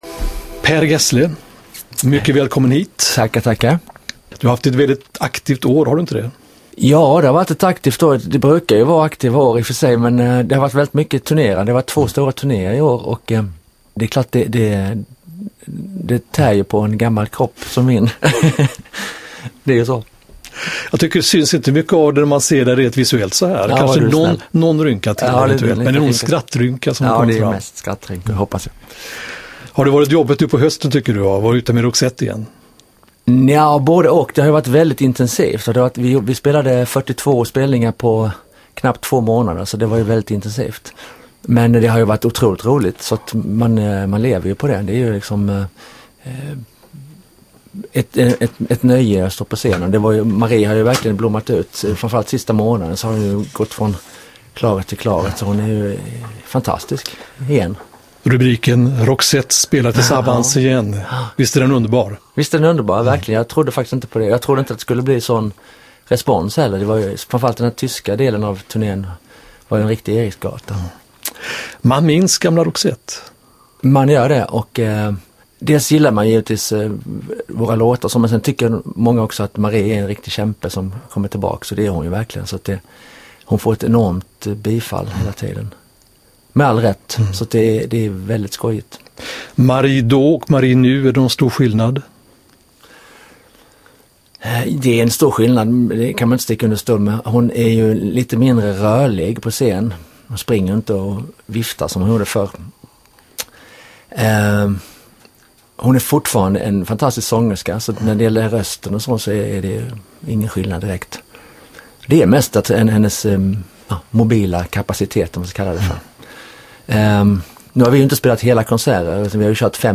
These are the highlights of the 13 minute interview: